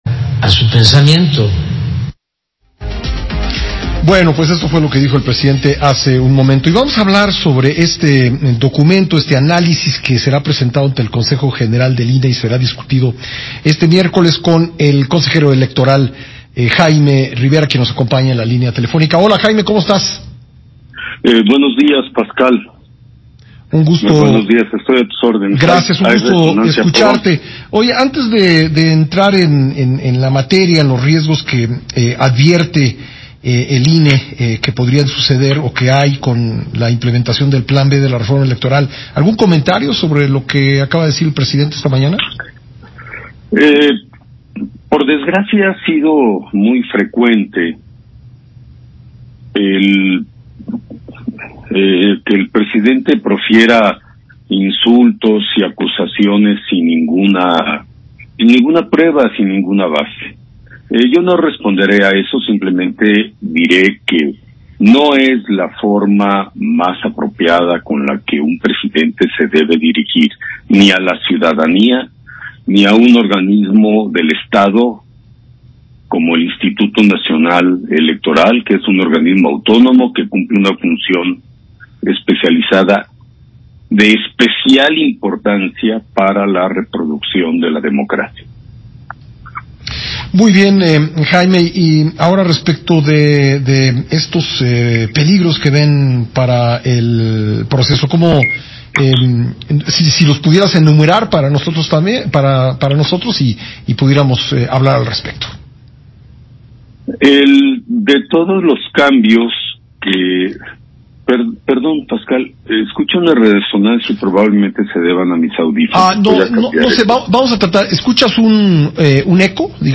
Lo más grave de los cambios que propone la iniciativa presidencial es la desaparición de las 300 juntas distritales; Jaime Rivera en entrevista con Pascal Beltrán del Río